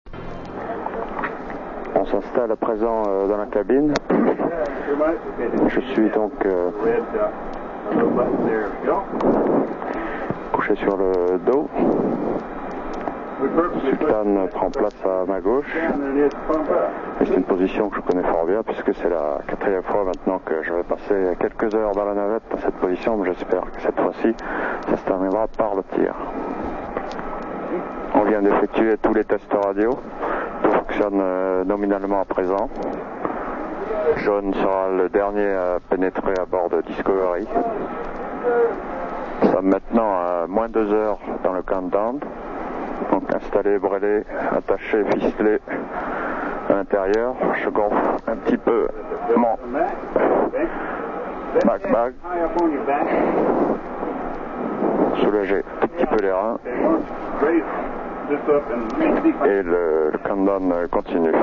La durée totale du disque étant d' une heure environ (soit 50 Mo en MP3), je l' ai divisé en 14 partie représentant les différentes phases de la mission commentées par l' astronaute lui même.